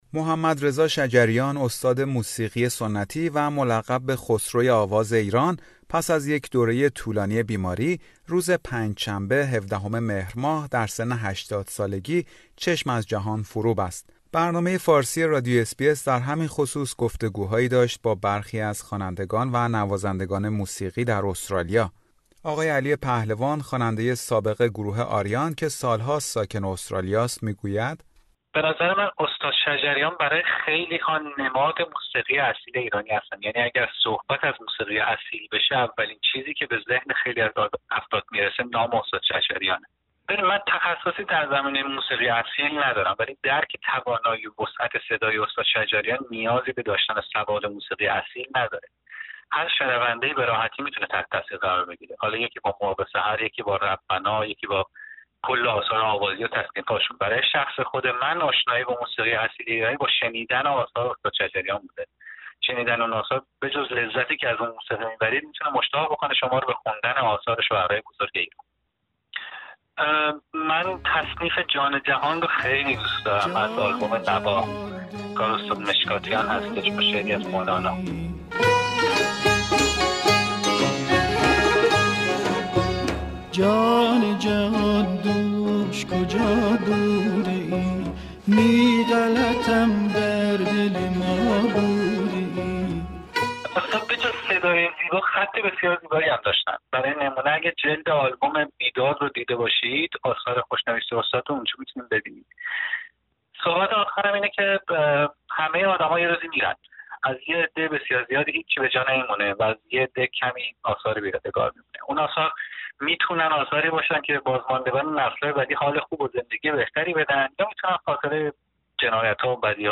گفتگو با هنرمندان ایرانی ساکن استرالیا در مورد استاد شجریان و میراثی که برجای گذاشت